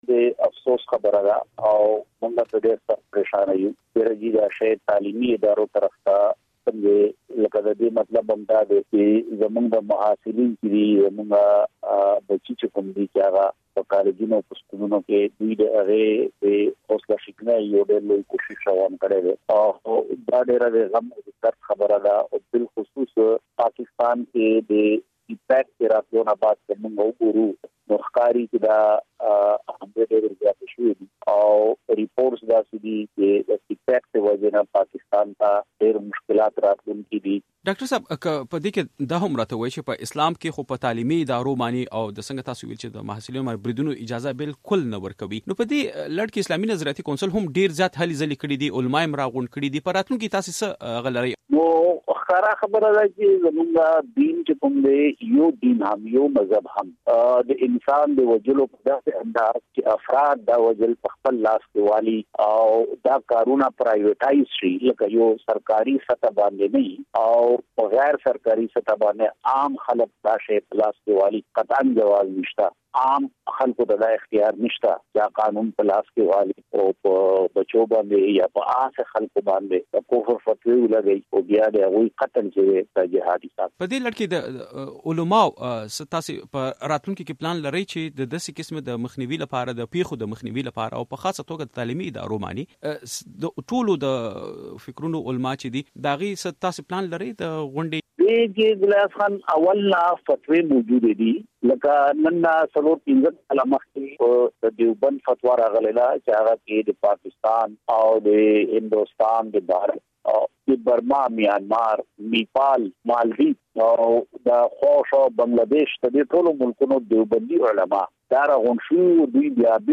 دا څرګندونې د اسلامي نظرياتي کونسل مشر ډاکټر قبله اياز د شنبې په ورځ له مشال راډيو سره په ځانګړي مرکه کې کړې دي.